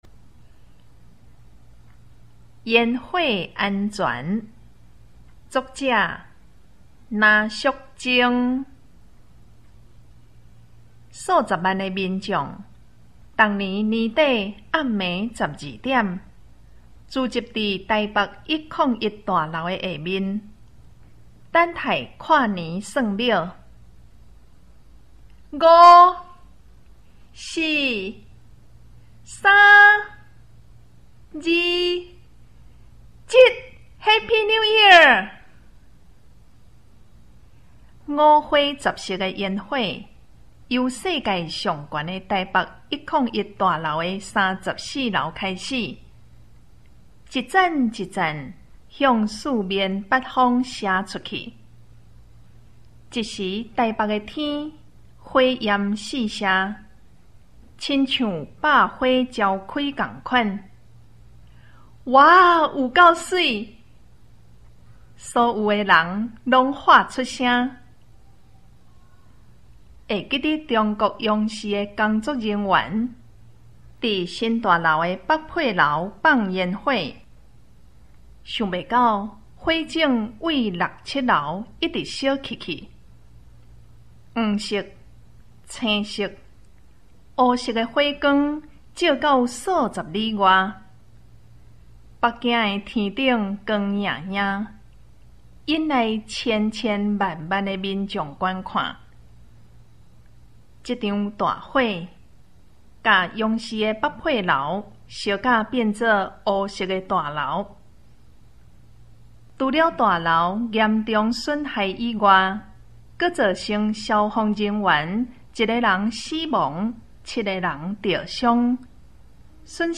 【公告】校內語文競賽閩南語朗讀篇章與音檔